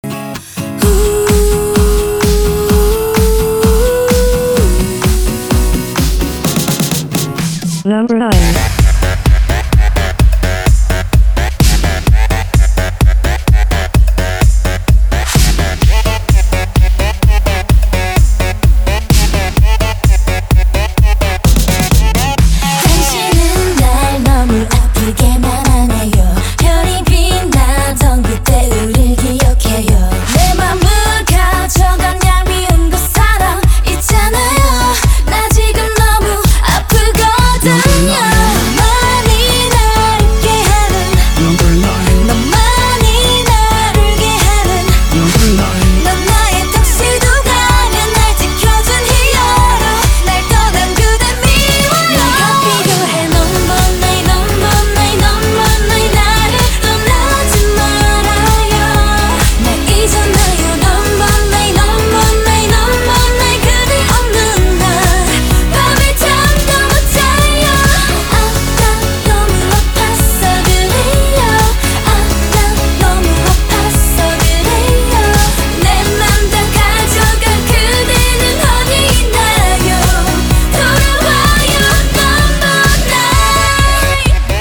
• Качество: 320, Stereo
женский вокал
корейские
K-Pop